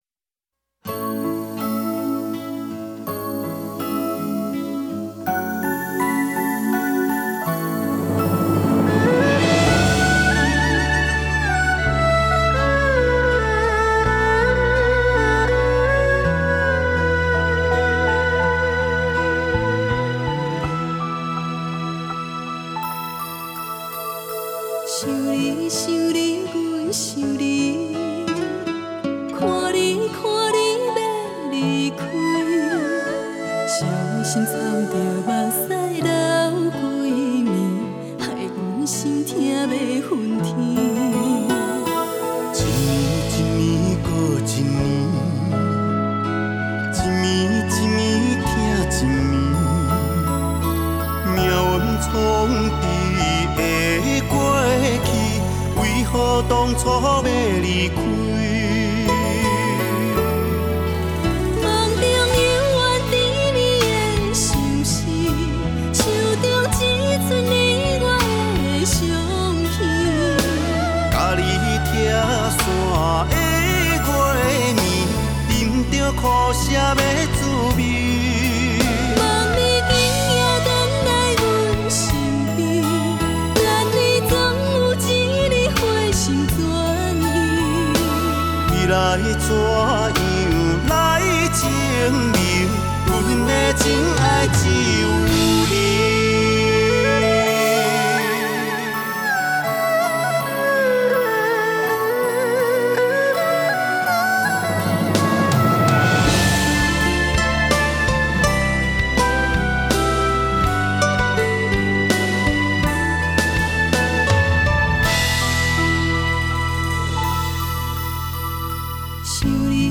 收录多首最新超夯经典原声原影台语流行金曲!
动人的情歌，让人更感窝心